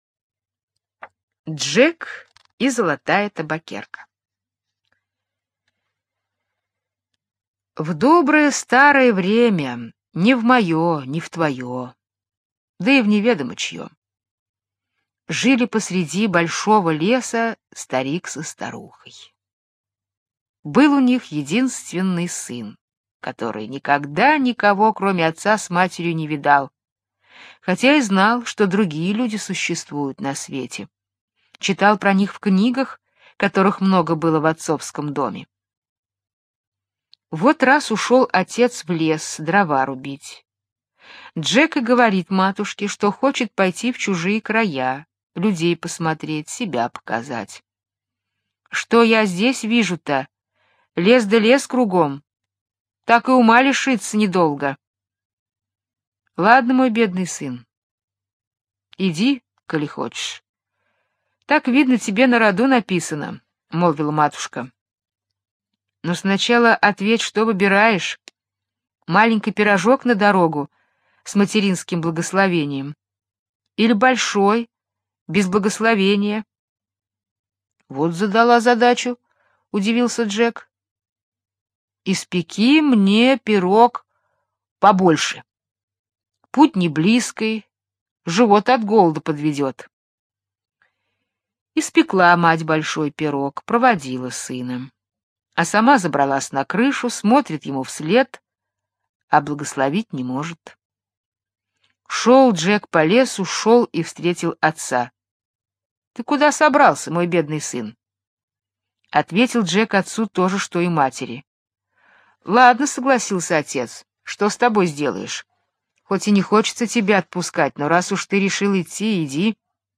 Джек и золотая табакерка - британская аудиосказка - слушать онлайн